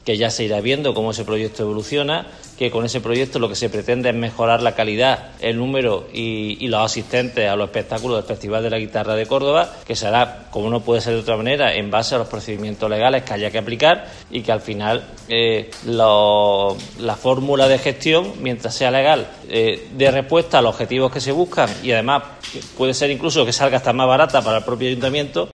Escucha a Miguel Ángel Torrico, delegado de Presidencia, sobre el Festival de la Guitarra